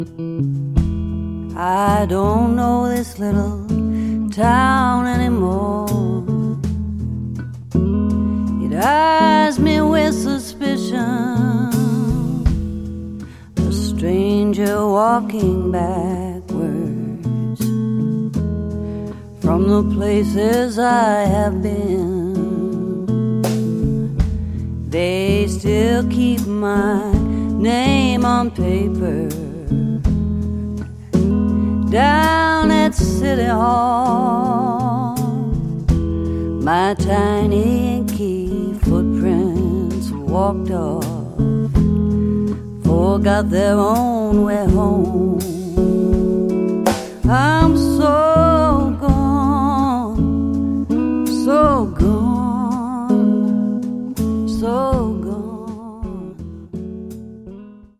Drums
Vocals, Guitar